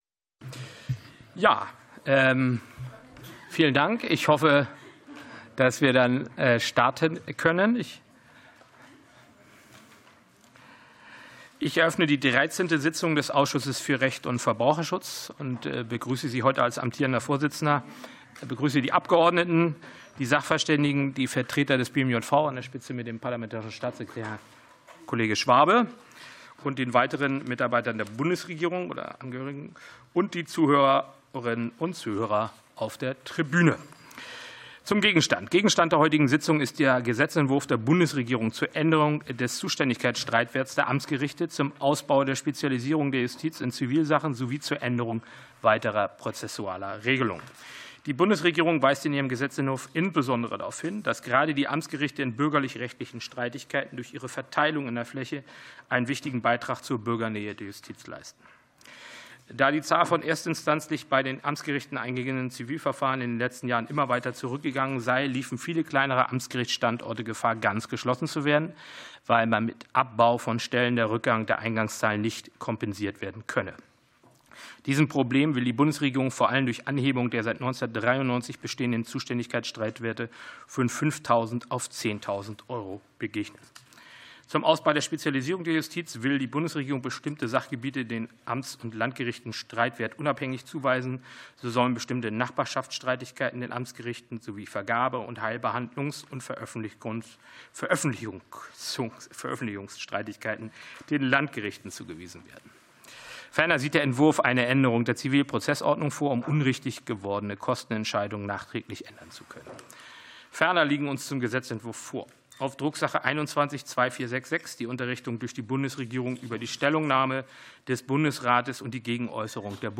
Anhörung des Ausschusses für Recht und Verbraucherschutz